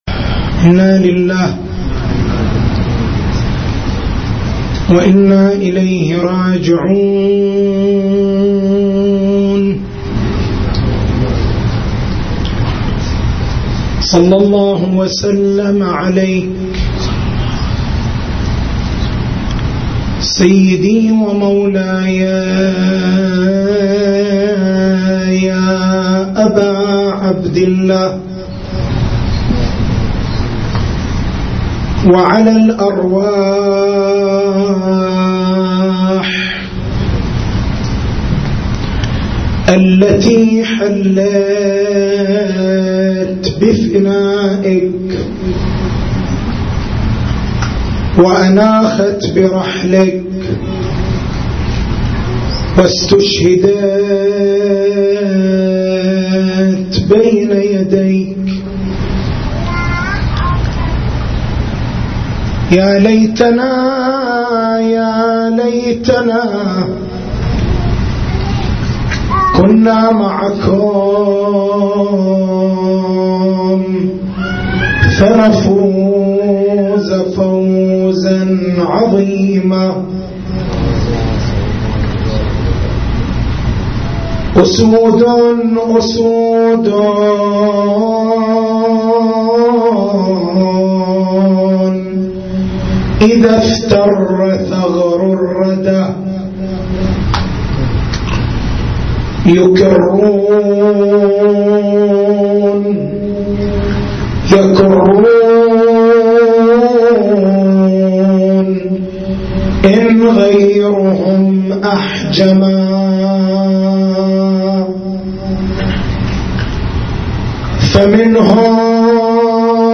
تاريخ المحاضرة: 06/01/1435 بيان أنّ المرجعية مشروع إلهيّ تنظير الروايات لمنصب المرجعيّة كيف يكون الراد على المرجع رادًّا على الله؟